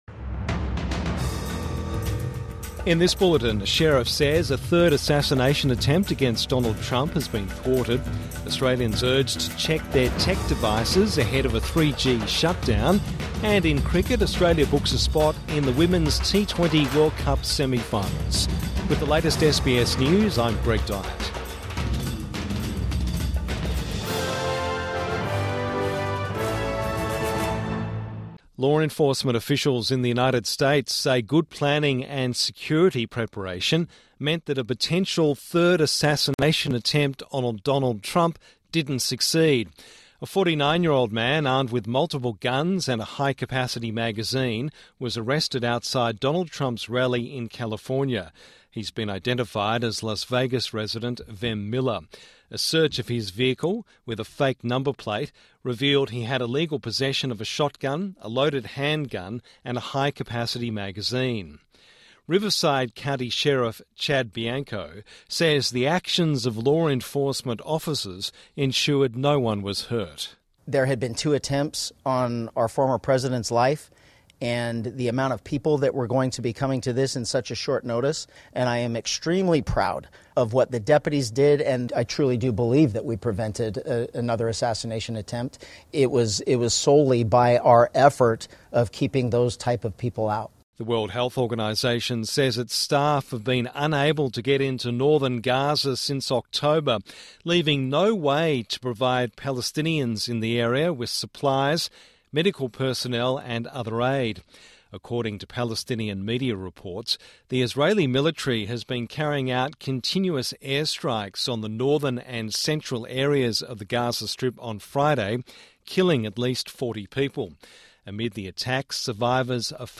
Midday News Bulletin 14 October 2024